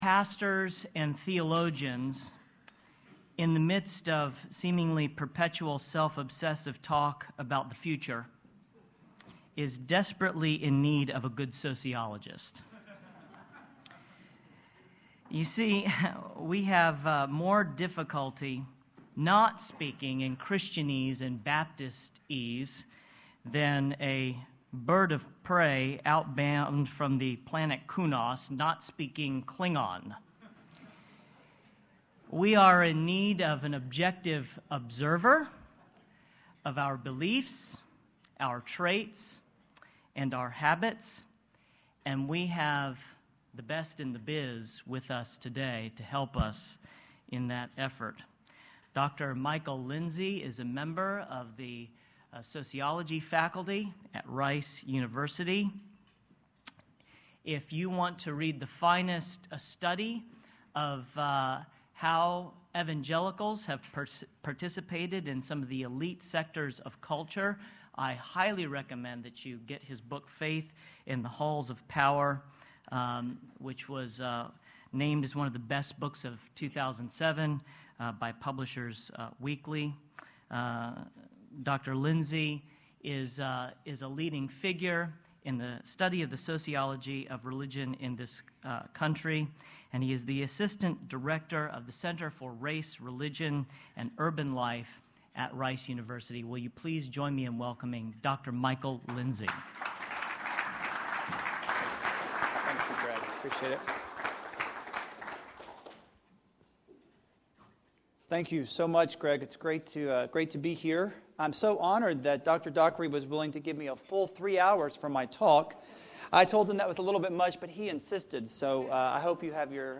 Future of Denominationalism Conference
Address: Denominationalism and the Changing Religious Landscape in North America